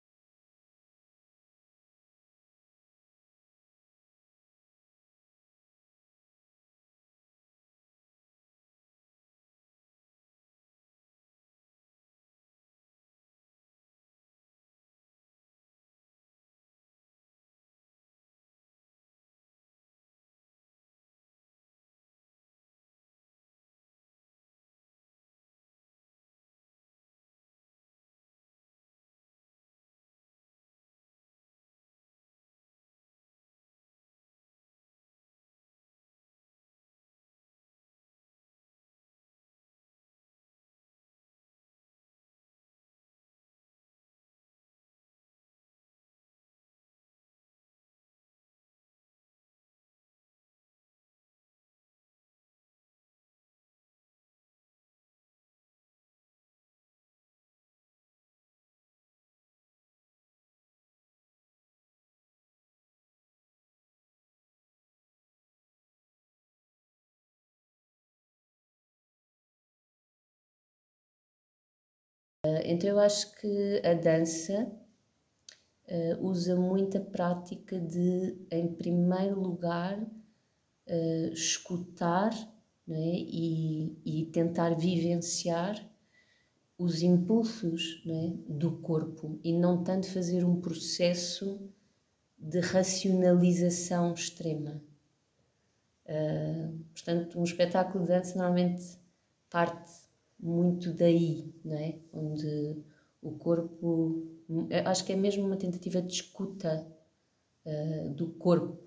primeira-parte-entrevista.mp3